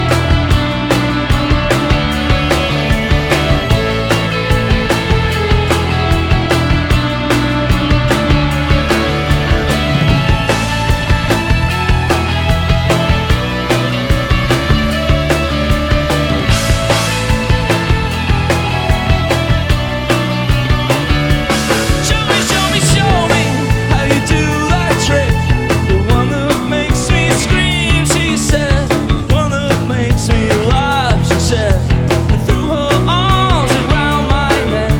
Alternative Rock Adult Alternative
Жанр: Рок / Альтернатива